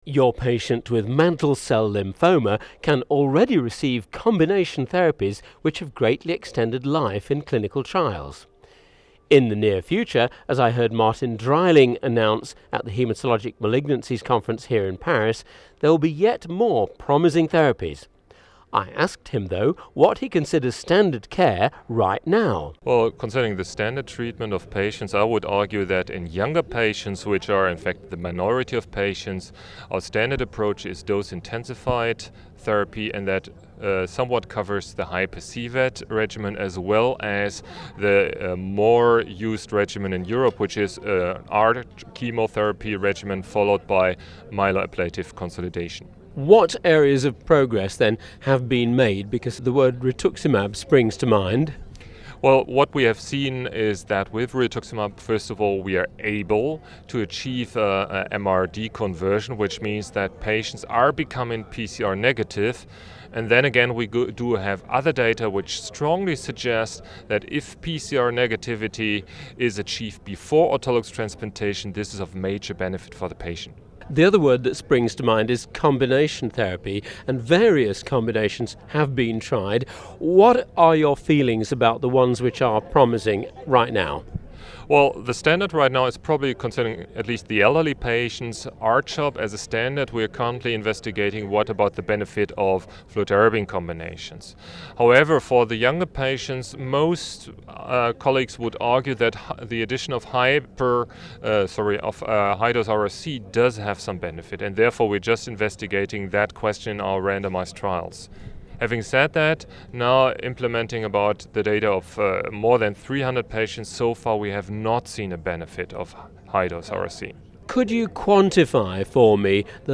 Reporting From: 4th European Congress on Hematologic Malignancies, Paris 22-24 February, 2008